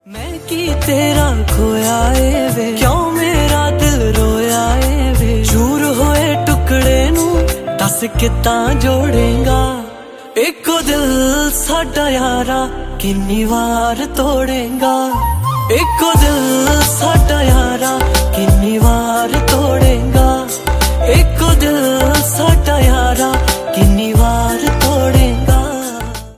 Ringtone File
Punjabi Songs